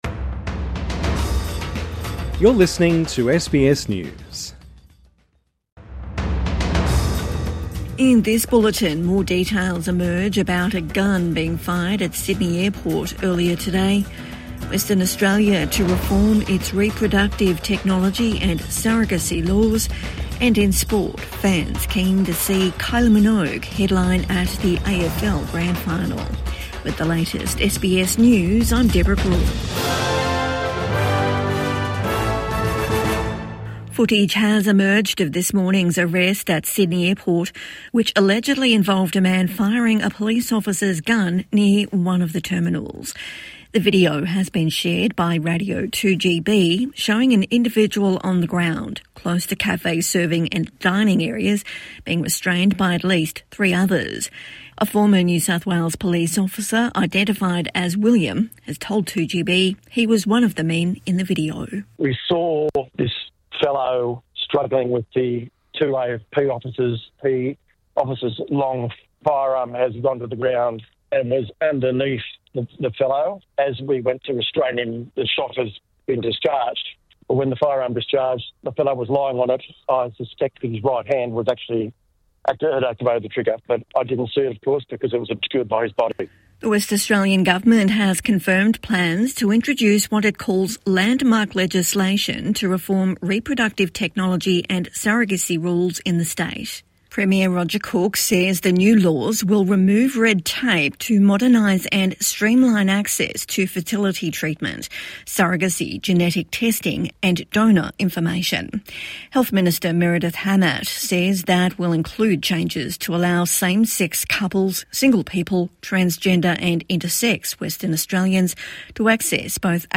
Western Australia to reform state's surrogacy laws | Evening News Bulletin 13 August 2025